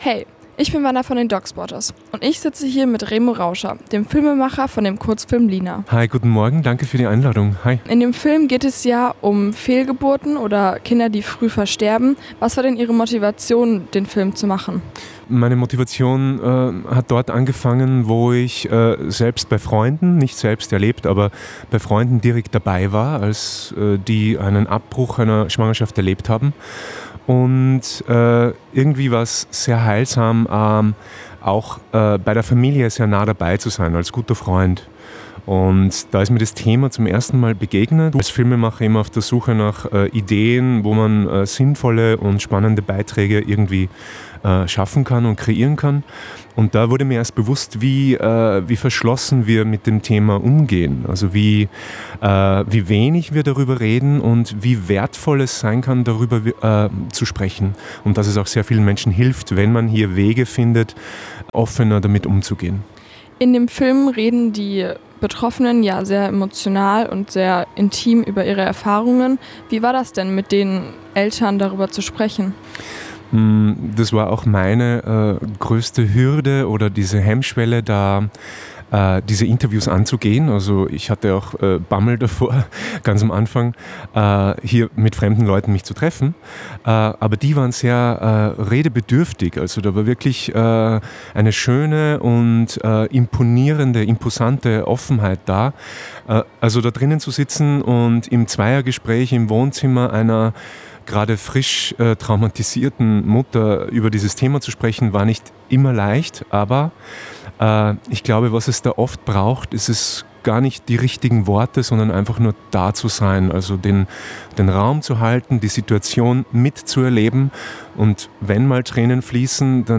Von DOK Spotters 2025Audio, Interview